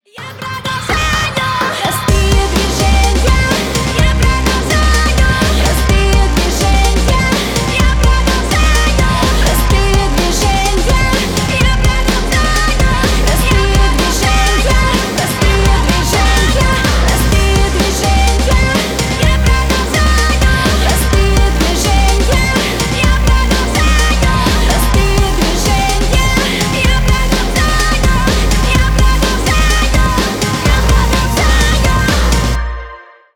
Поп Музыка
громкие # клубные